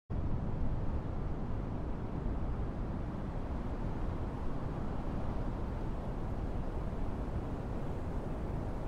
bad weather in all cities sound effects free download